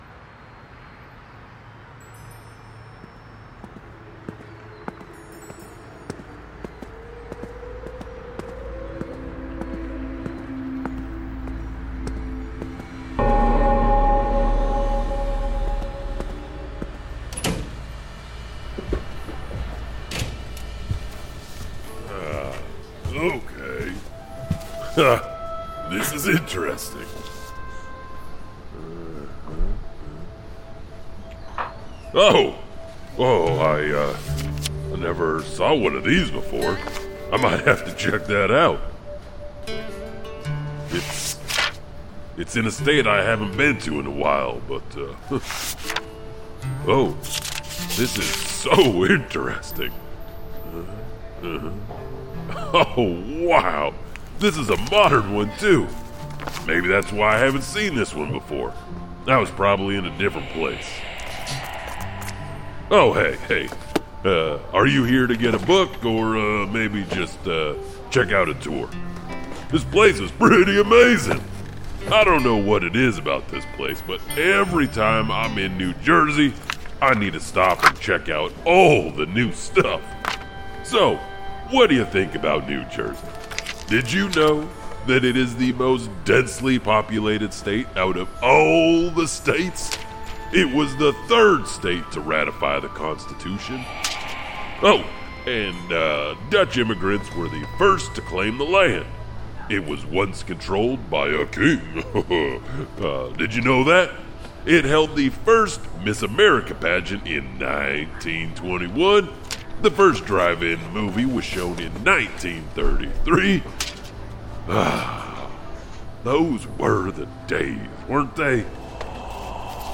Tales From the Janitor - Tales from the Janitor: Chilling Urban Legends & Unsolved Mysteries from New Jersey | Horror Audio Drama
Whether you're fascinated by real ghost stories, true paranormal encounters, or eerie historical secrets, Tales from the Janitor delivers an immersive audio experience filled with suspense, storytelling, and spine-tingling atmosphere.